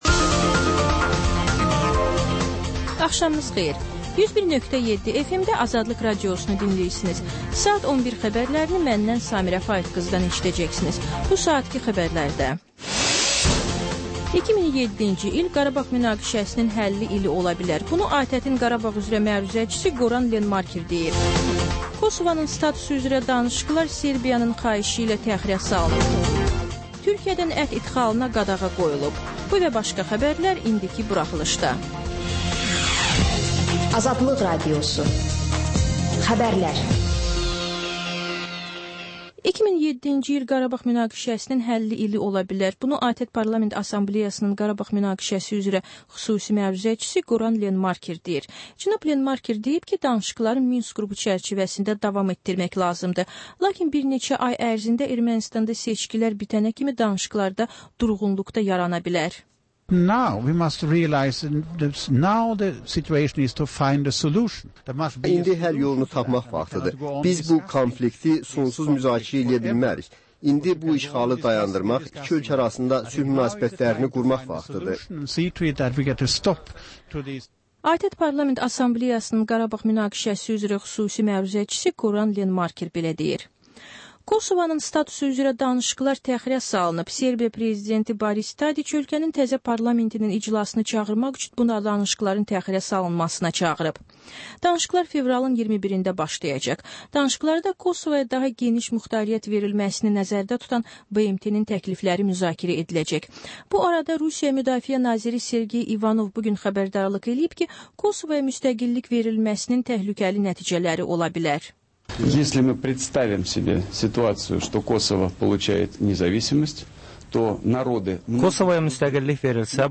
Xəbərlər, reportajlar, müsahibələr. Və: Günün Söhbəti: Aktual mövzu barədə canlı dəyirmi masa söhbəti.